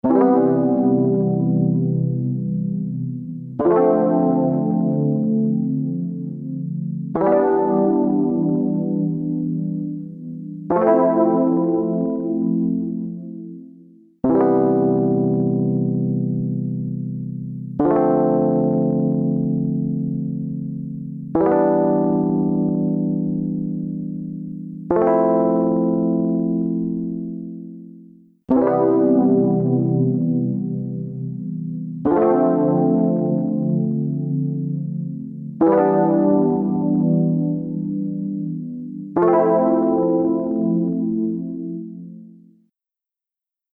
TriceraChorusは、サウンドに極上の豊かさと空間的な奥行きをもたらす、洗練されたコーラス・ソリューションです。
TriceraChorus | Rhodes | Preset: Raven Chorus
TriceraChorus-Eventide-Rhodes-Raven-Chorus.mp3